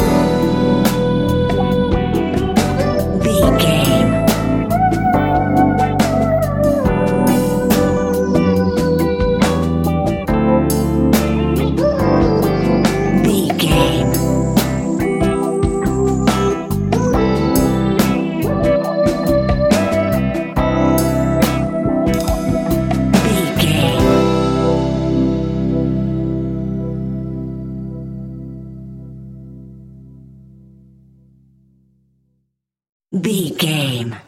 Ionian/Major
instrumentals